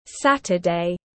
Thứ 7 tiếng anh gọi là saturday, phiên âm tiếng anh đọc là /ˈsæt.ə.deɪ/
Saturday /ˈsæt.ə.deɪ/